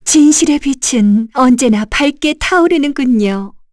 Yuria-Vox_Victory_kr_b.wav